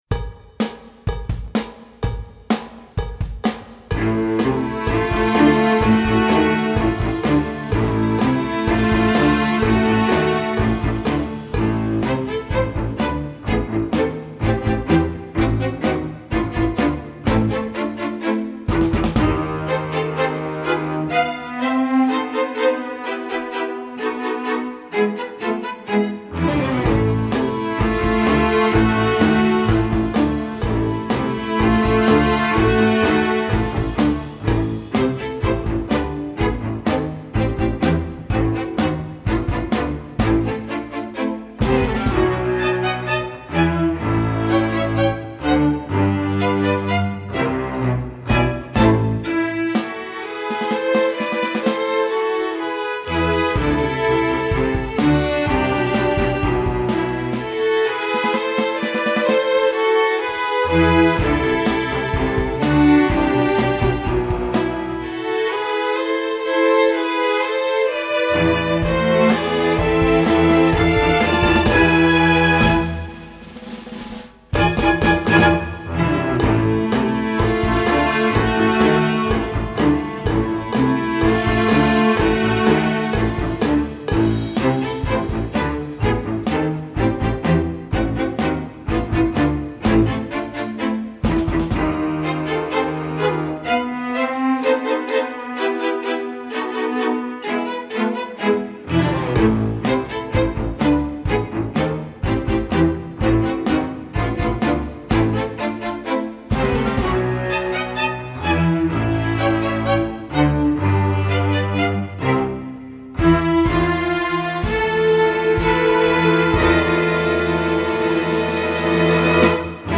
Instrumentation: string orchestra
masterwork arrangement, novelty, secular
Piano accompaniment part: